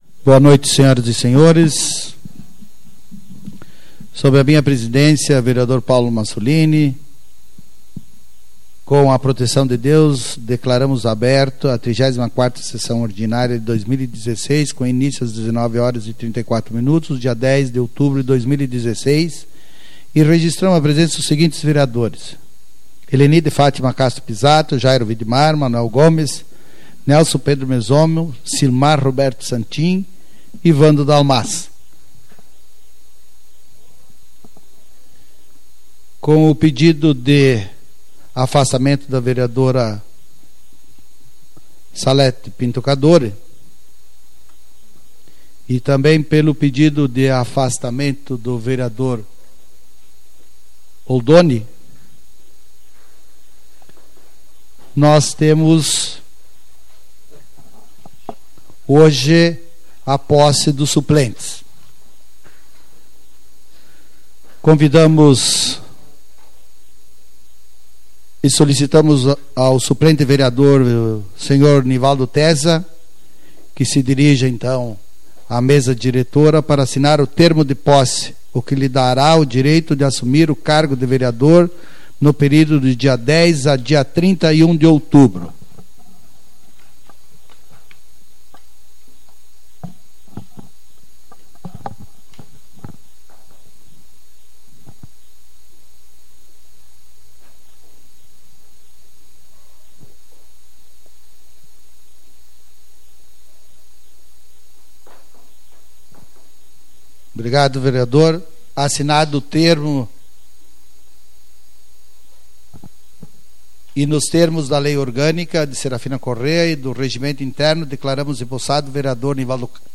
Tipo de Sessão: Ordinária